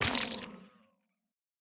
Minecraft Version Minecraft Version 25w18a Latest Release | Latest Snapshot 25w18a / assets / minecraft / sounds / block / sculk / break12.ogg Compare With Compare With Latest Release | Latest Snapshot
break12.ogg